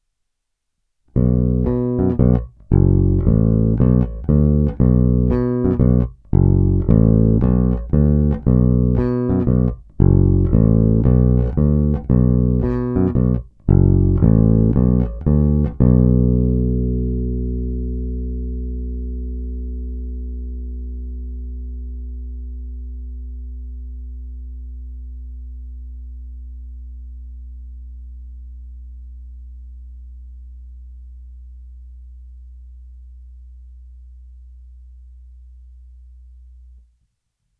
Je hutný, vrčivý, zvonivý, s bohatými středy, s příjemnými výškami a masívními basy.
Není-li uvedeno jinak, následující ukázky jsou pořízeny rovnou do vstupu zvukové karty a kromě normalizace ponechány bez jakéhokoli postprocesingu.
Hra mezi snímačem a kobylkou